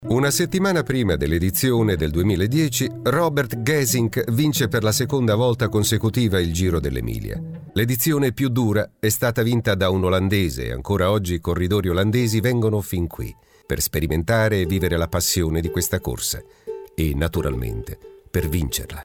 Natural clear and pleasing voice
Sprechprobe: Sonstiges (Muttersprache):